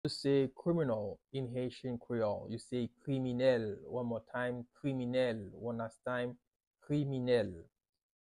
“Criminal” in Haitian Creole – “Kriminèl” pronunciation by a native Haitian teacher
“Kriminèl” Pronunciation in Haitian Creole by a native Haitian can be heard in the audio here or in the video below:
How-to-say-Criminal-in-Haitian-Creole-–-Kriminel-pronunciation-by-a-native-Haitian-teacher.mp3